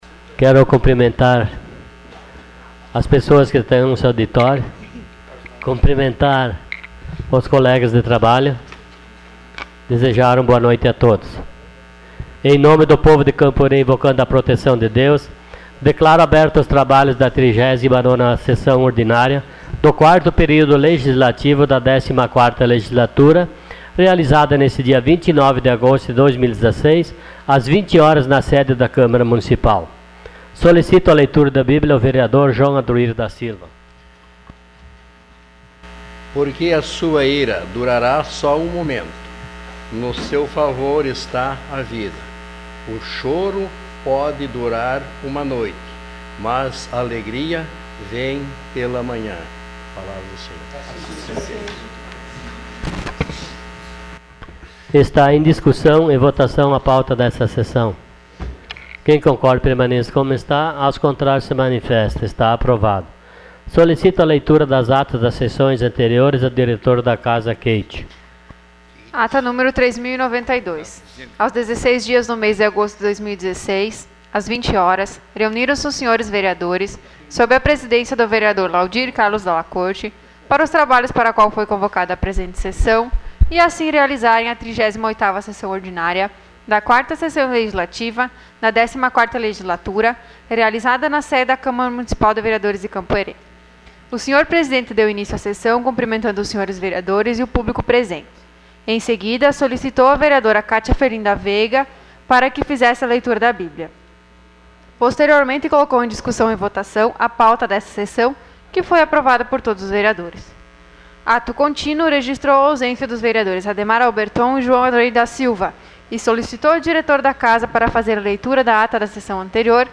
Sessão Ordinária dia 29 de agosto de 2016.